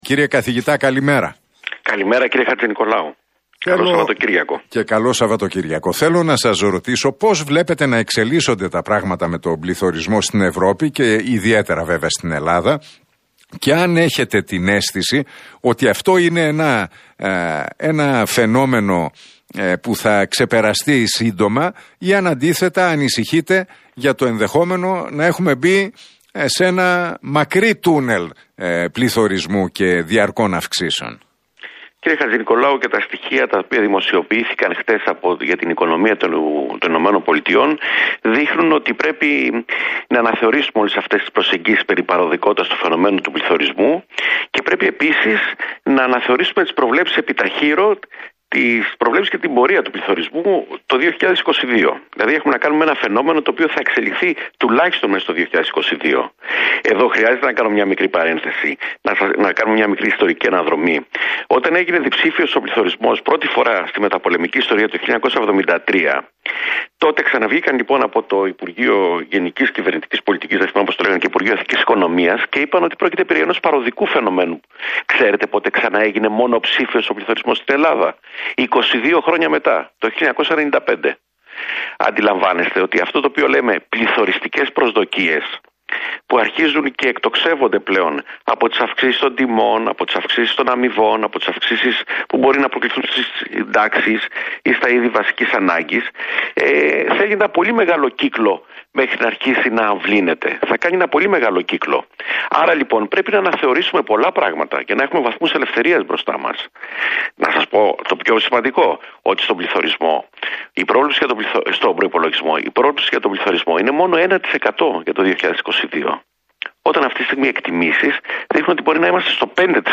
μιλώντας στον Realfm 97,8 και στον Νίκο Χατζηνικολάου δήλωσε ότι “τα στοιχεία που δημοσιεύθηκαν χθες για την οικονομία των ΗΠΑ